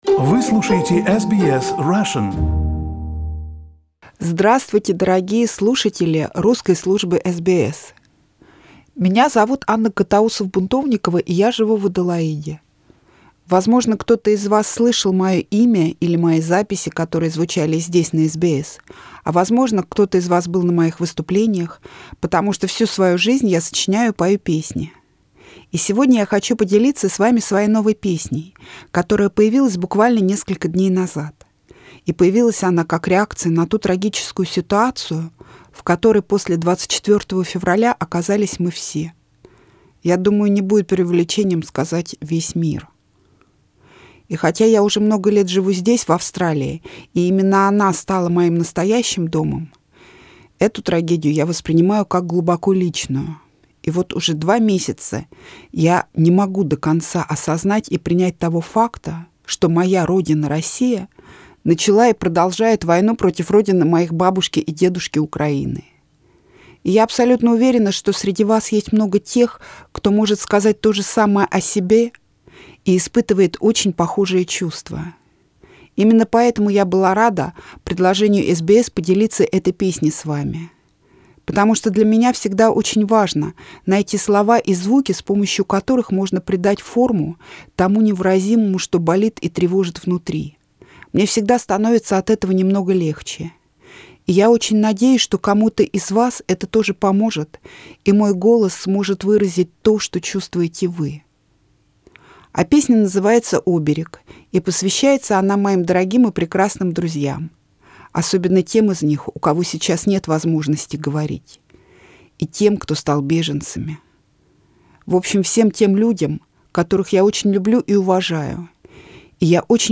This song was her attempt to somehow articulate this pain. Recorded for SBS Russian, published with the permission of the author.